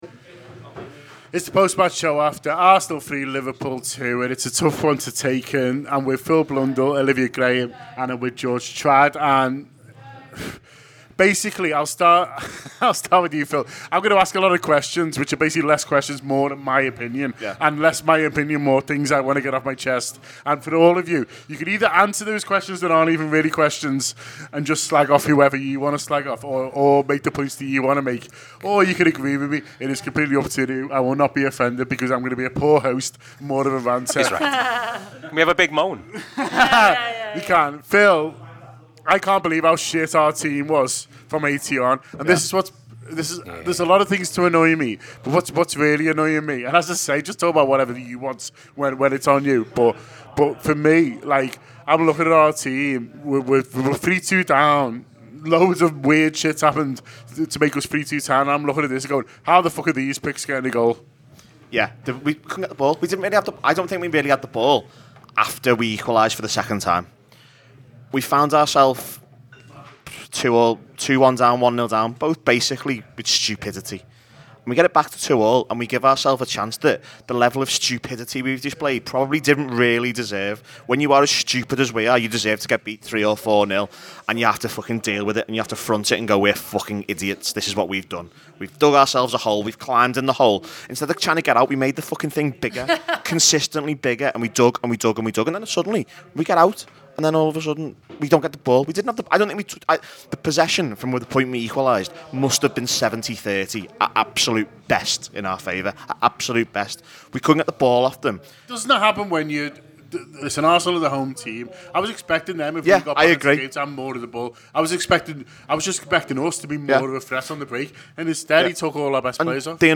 Below is a clip from the show – subscribe for more Arsenal v Liverpool reaction…